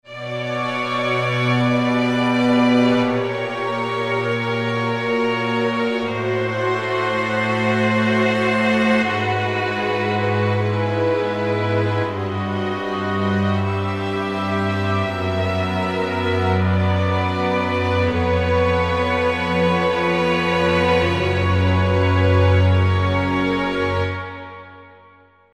Помогите появилось шипение!
Появилось шипение в проекте!